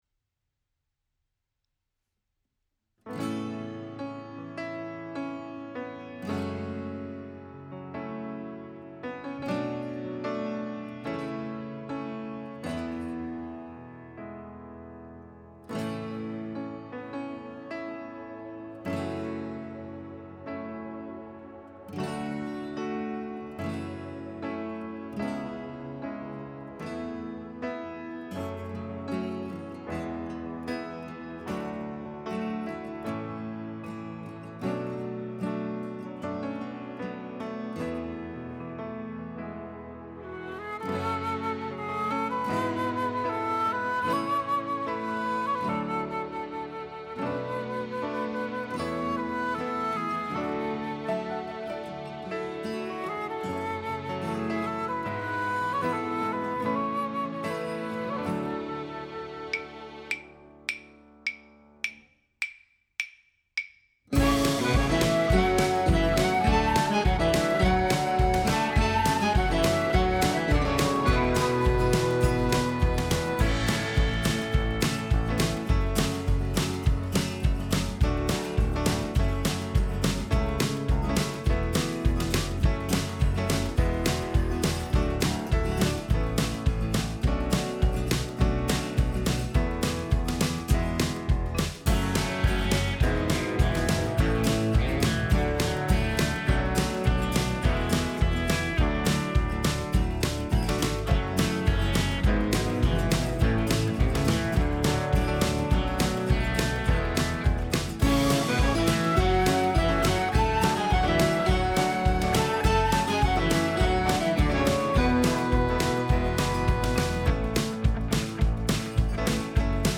Backing Track to sing along with